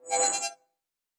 Sci-Fi Sounds / Interface / Error 17.wav
Error 17.wav